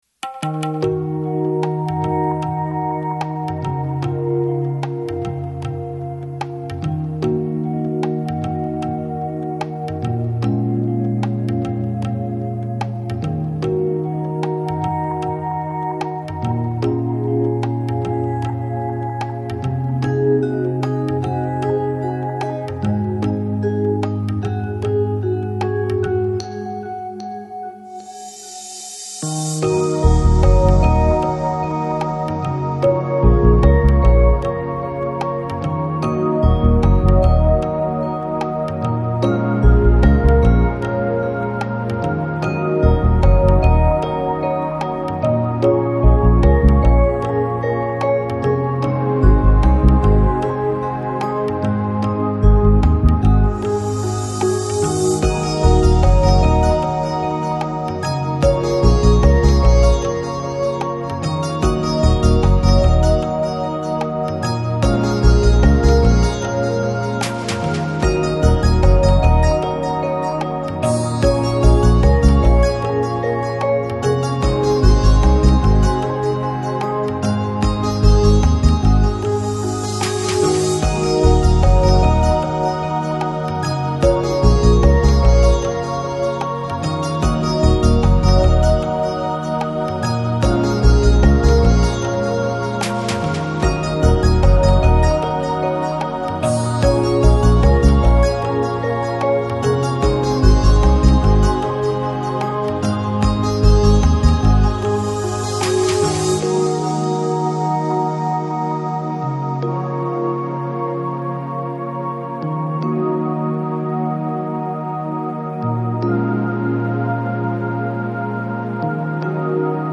Жанр: Electronic, Lounge, Chill Out, Downtempo